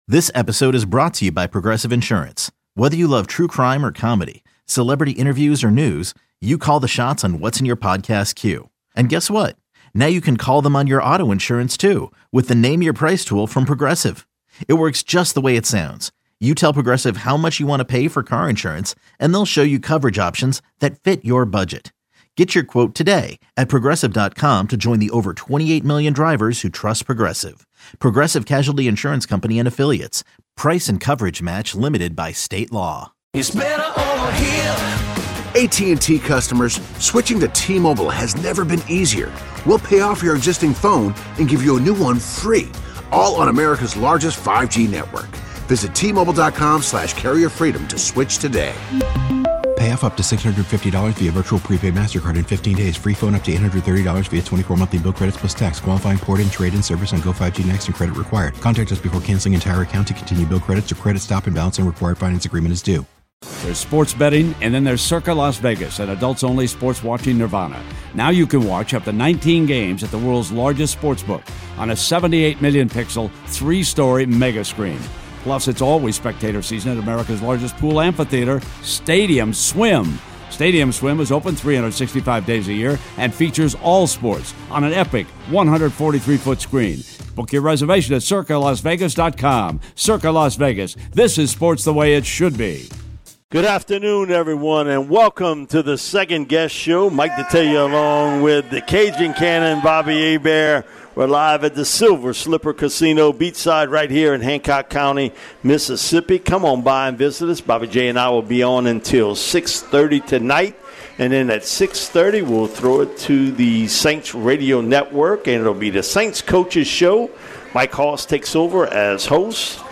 The guys spoke to WWL listeners about Taysom Hill, Carl Granderson, Dennis Allen, and Derek Carr.